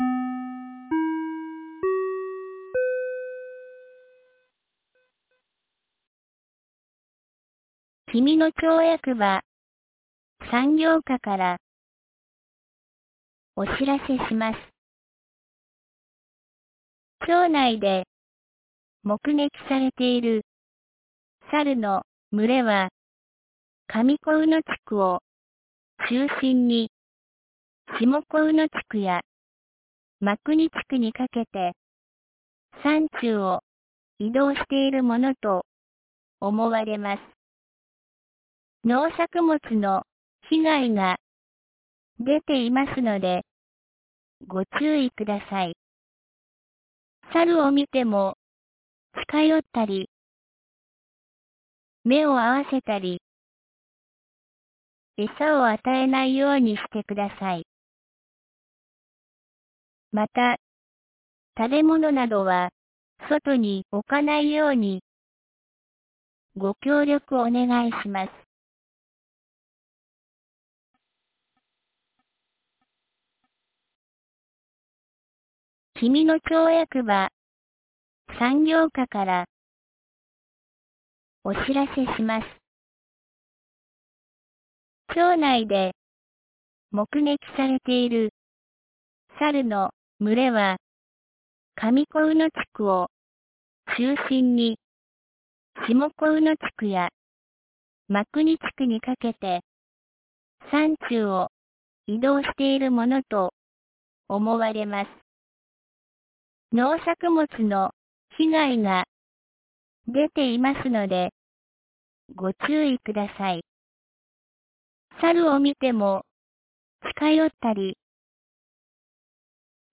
2025年09月21日 12時36分に、紀美野町より上神野地区、国吉地区、真国地区、志賀野地区、下神野地区へ放送がありました。